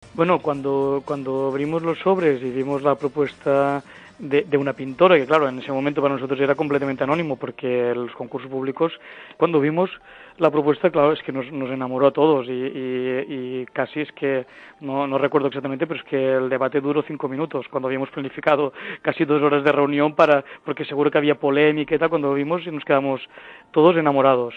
contó en el programa de la COPE Fin de semana